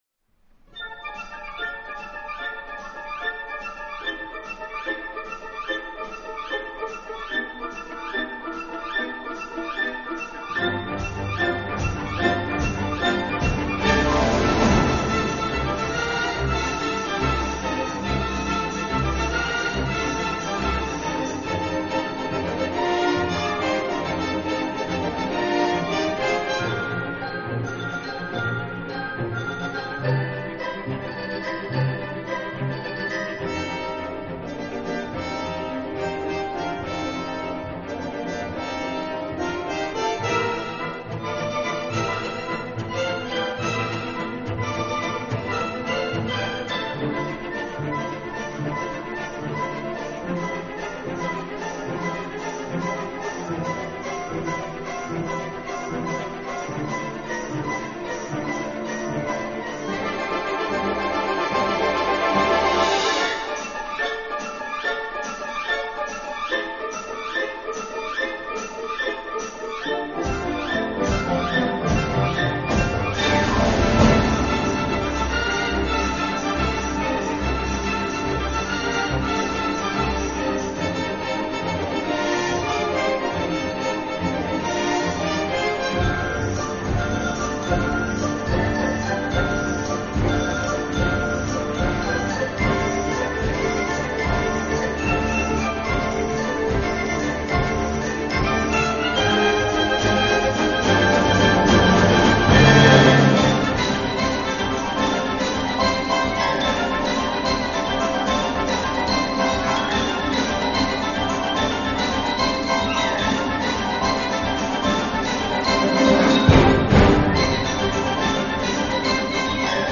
管弦楽